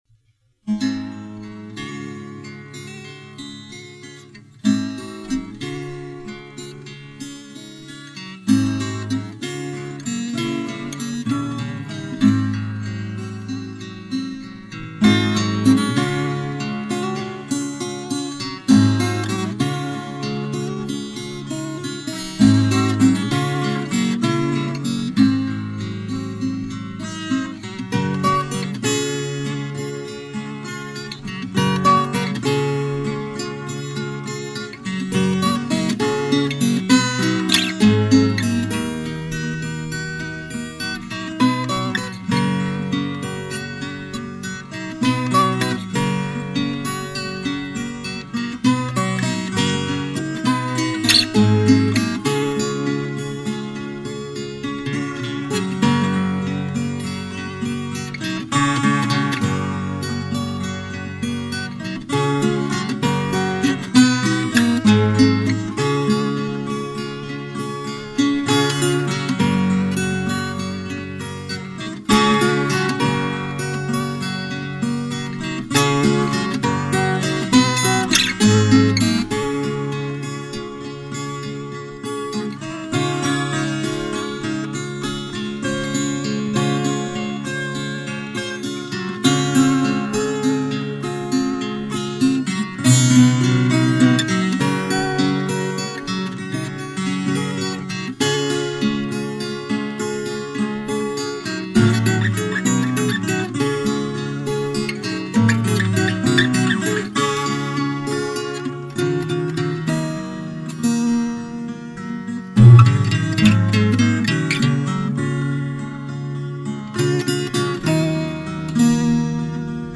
лидер гитара
бас гитара
ударные
Формат MP3, напоминаем, записи старые, 1983-1986 год.